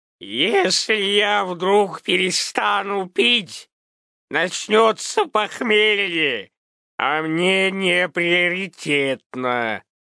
Образцы озвучания, прошедшие визирование у Супера и допущенные к опубликованию: